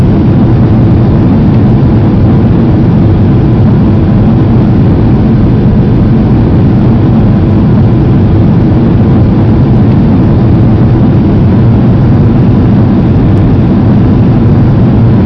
Add aft wing sounds
cfm-wingaft-rumble.wav